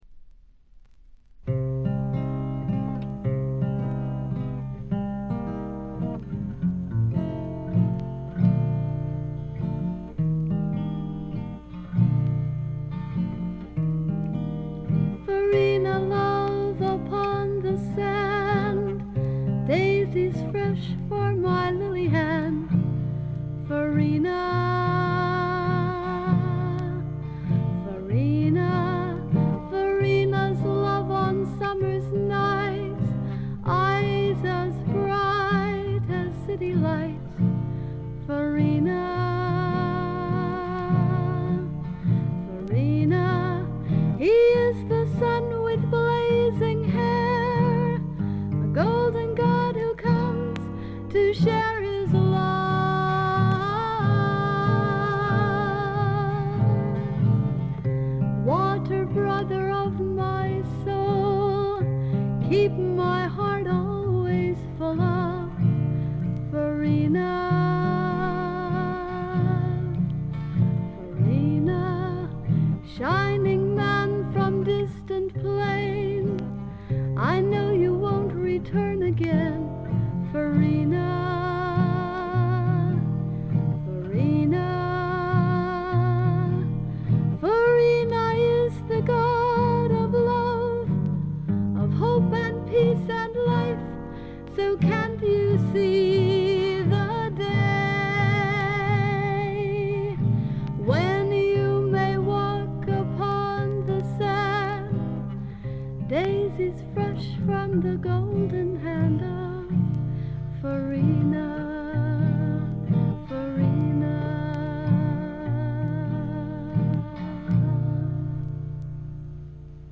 静音部での軽微なチリプチ程度。
甘酸っぱい香りが胸キュンのまばゆいばかりの青春フォークの傑作。
試聴曲は現品からの取り込み音源です。
Vocals, Guitar, Composed By ?